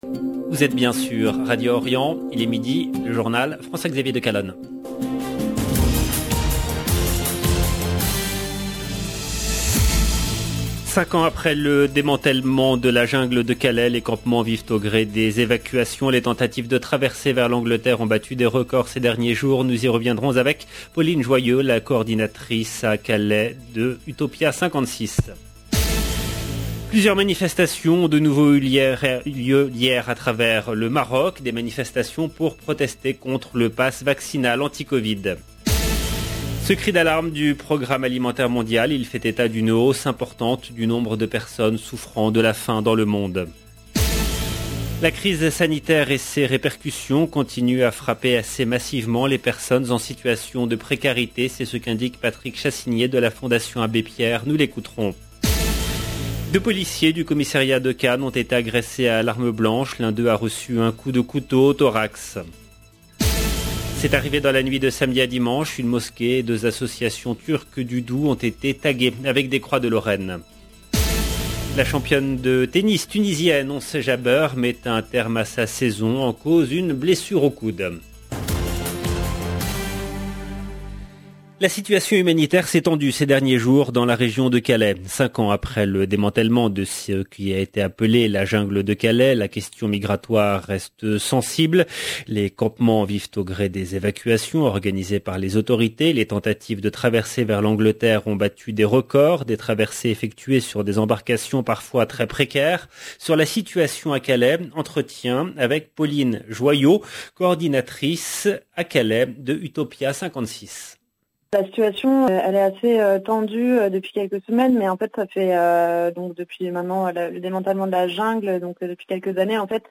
LE JOURNAL DE 12 H EN LANGUE FRANCAISE DU 8/11/2021